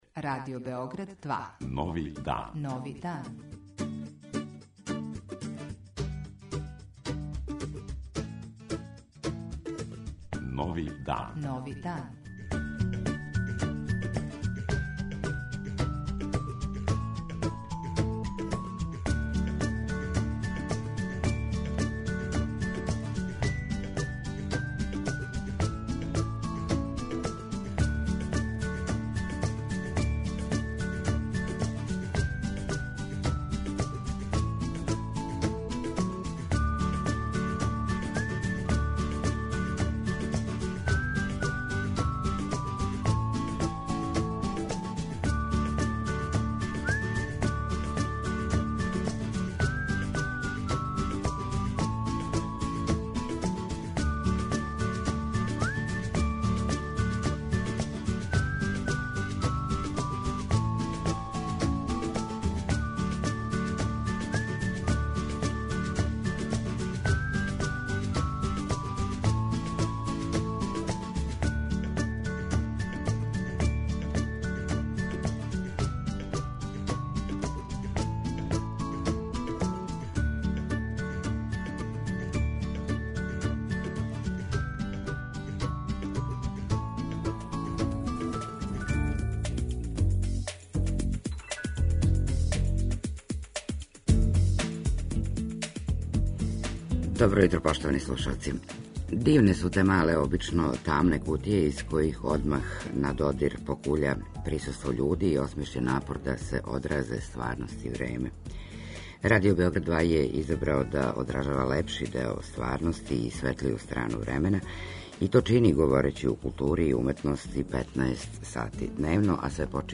Јутарњи магазин